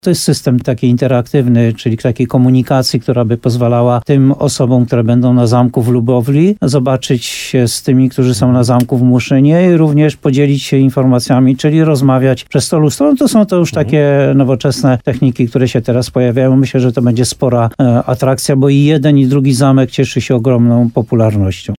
– Atrakcja będzie finansowana z polsko-słowackiego programu Interreg – komentuje burmistrz Muszyny Jan Golba.